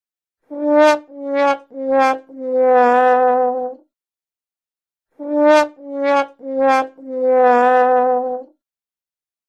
Sound Buttons: Sound Buttons View : Trombone Triste
trombonetriste.mp3